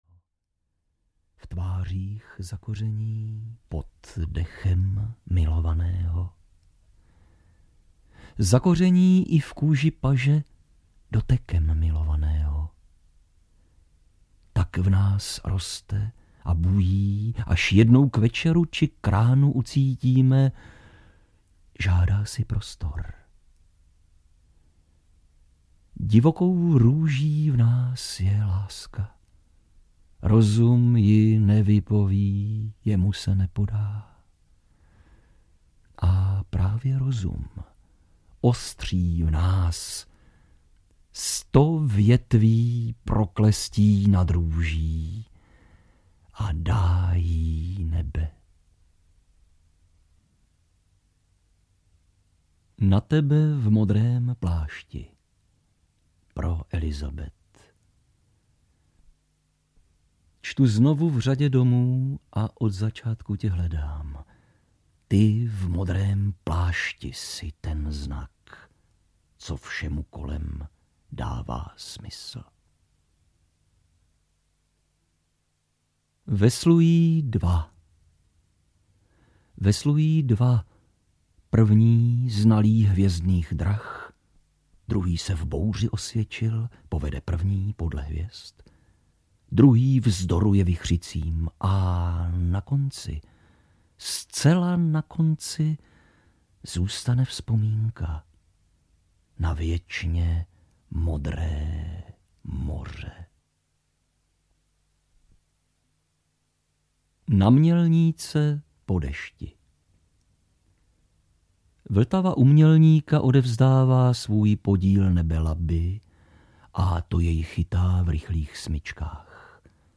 Samomluva k  druhým audiokniha
Ukázka z knihy
samomluva-k-druhym-audiokniha